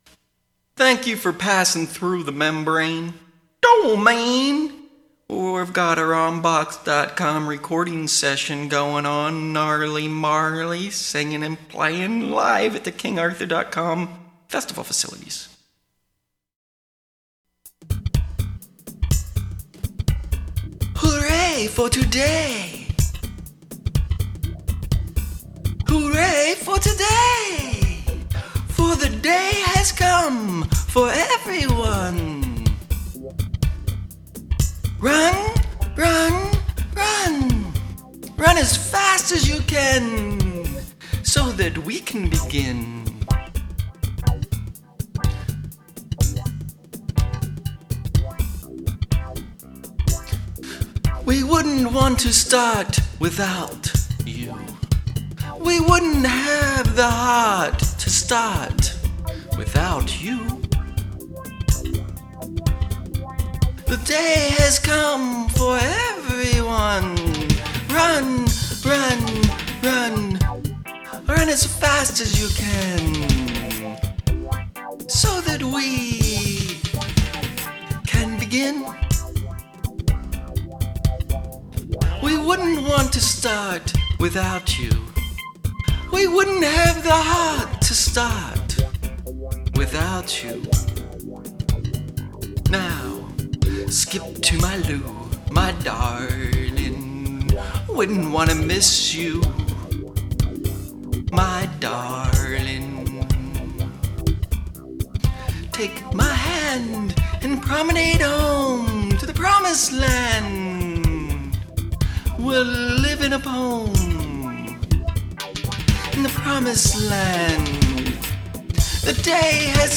C / G / D